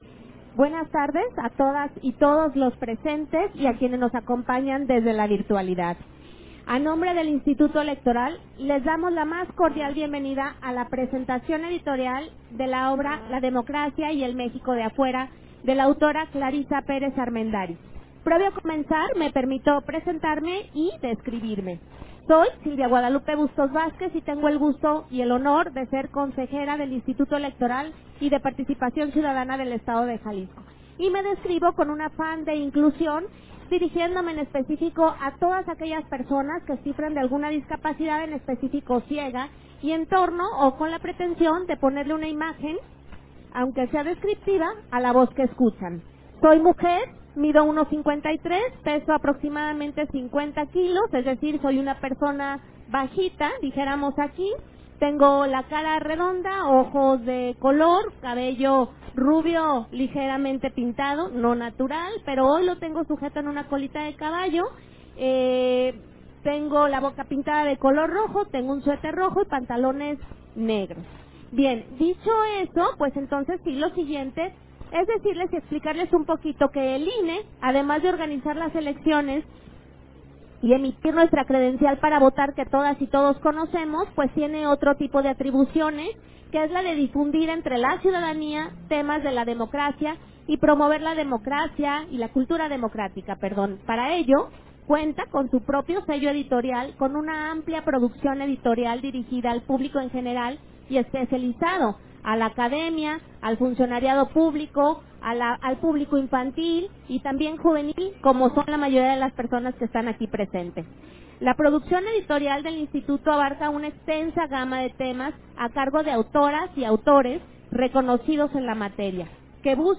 Versión estenográfica de la presentación editorial, Conferencias Magistrales: La democracia y el México de afuera, FIL Guadalajara 2024